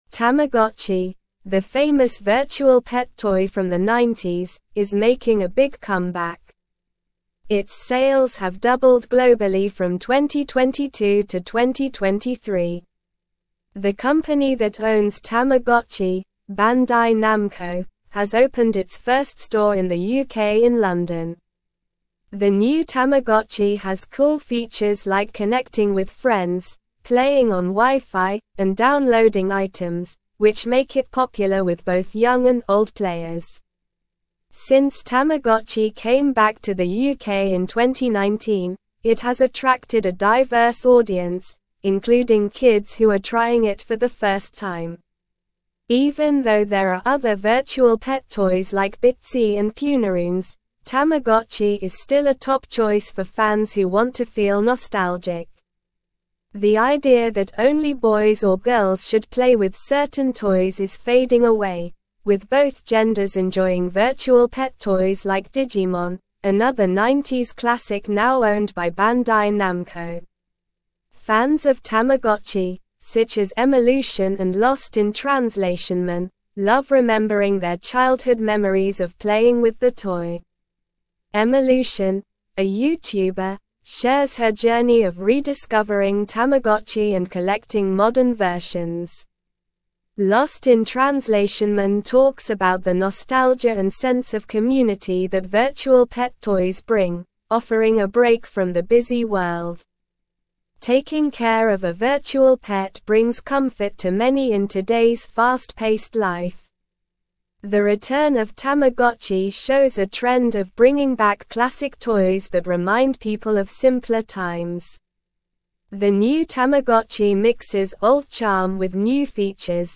Slow
English-Newsroom-Upper-Intermediate-SLOW-Reading-Tamagotchi-Makes-Strong-Comeback-with-Modern-Features-and-Nostalgia.mp3